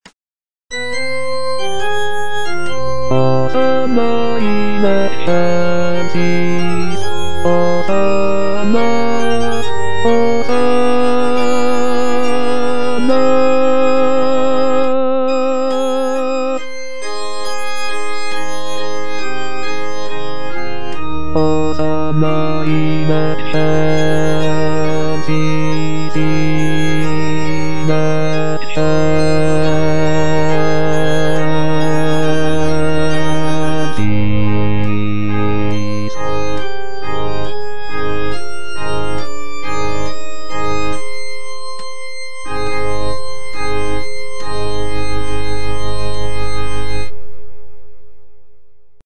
J.G. RHEINBERGER - MASS IN C OP. 169 Benedictus (choral excerpt only) - Bass (Voice with metronome) Ads stop: auto-stop